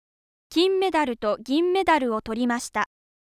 5. ガ行音